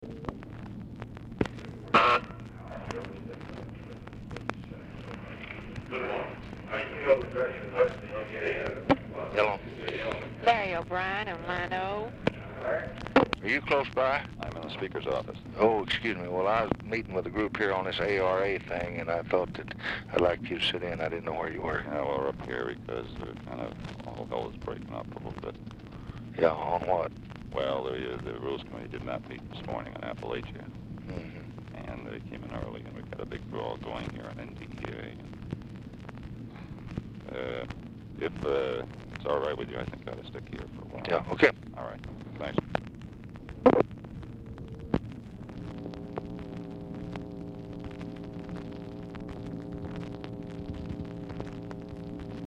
Telephone conversation # 4916, sound recording, LBJ and LARRY O'BRIEN, 8/14/1964, 10:40AM | Discover LBJ
Format Dictation belt